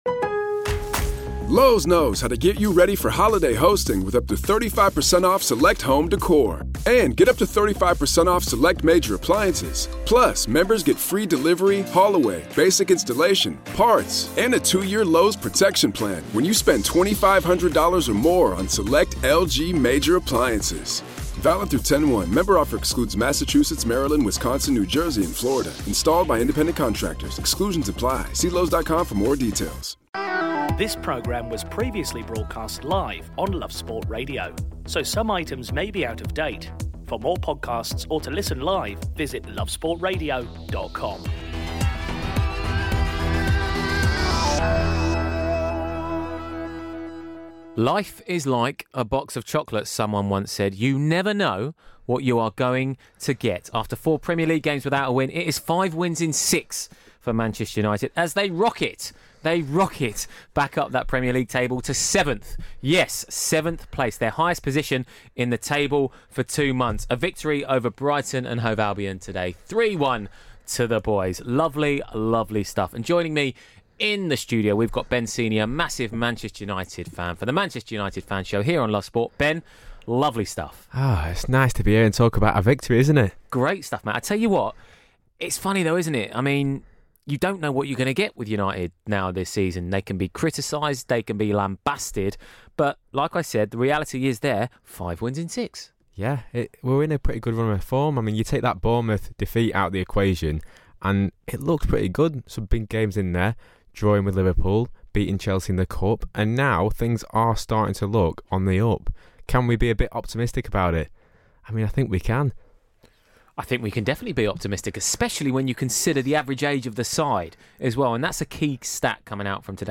is joined in studio